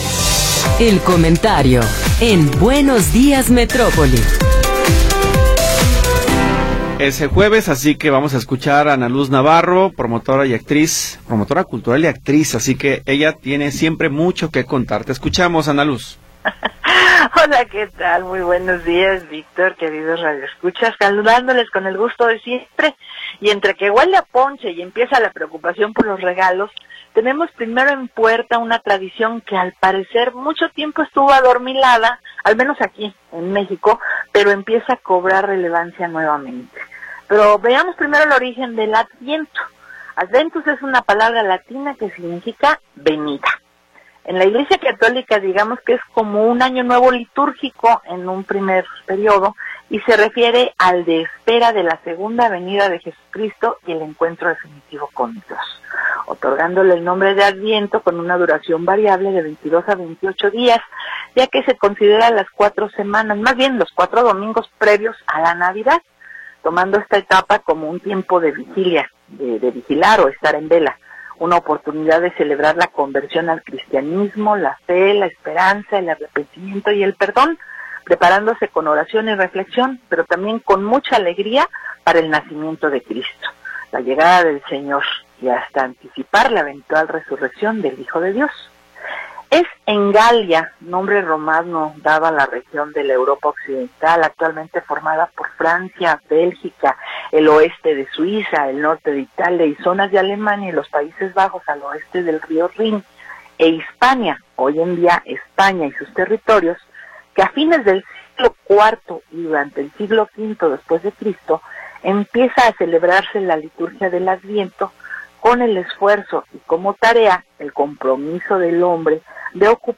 Comentario